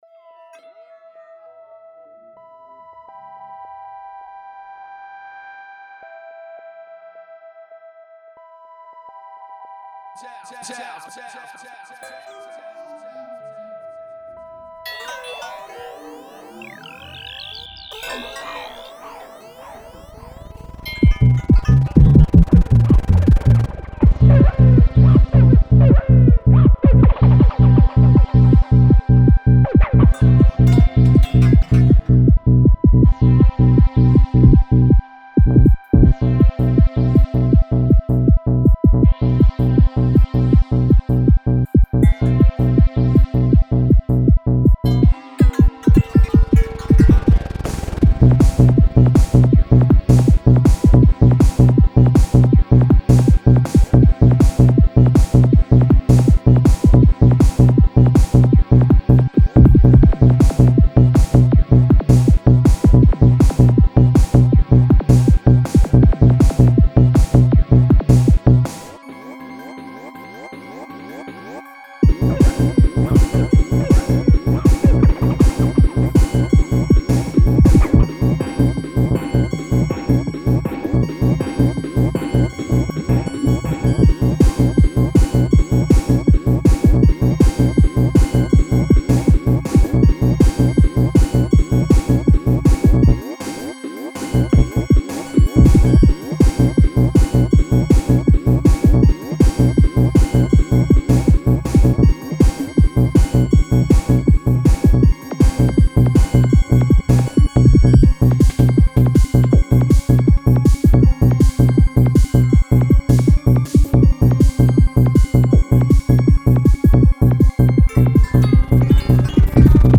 Hardtekk